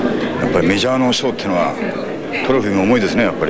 All sounds in WAV format are spoken by Yusaku himself (Also Andy Garcia and Michael Douglas).